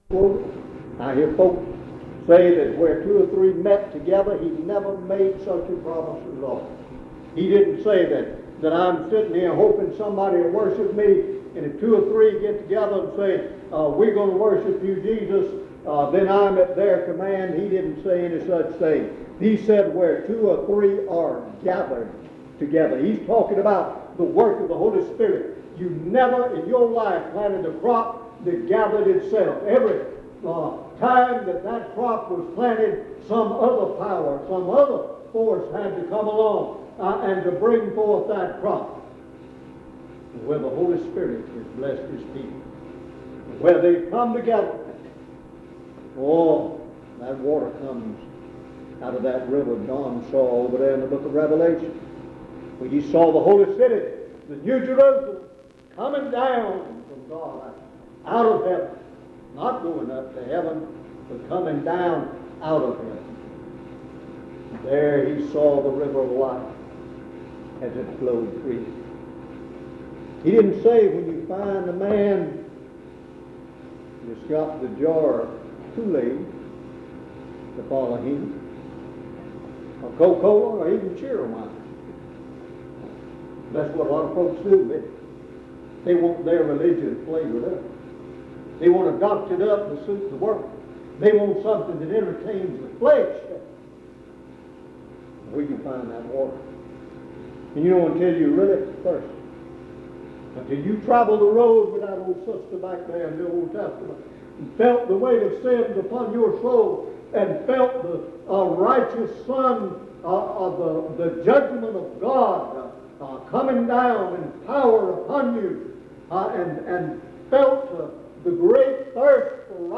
In Collection: Primitive Baptist churches audio recordings Thumbnail Titolo Data caricata Visibilità Azioni PBHLA-ACC.004_011-A-01.wav 2026-02-12 Scaricare PBHLA-ACC.004_011-B-01.wav 2026-02-12 Scaricare